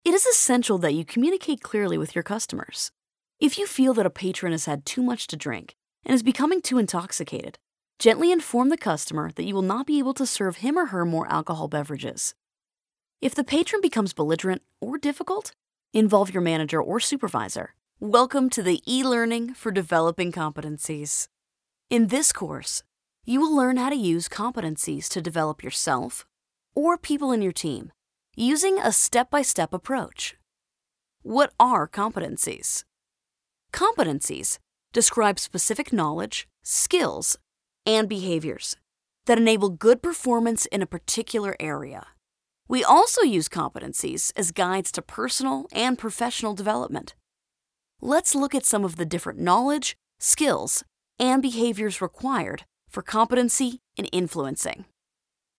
Englisch (Amerikanisch)
Kommerziell, Junge, Natürlich, Freundlich, Corporate
E-learning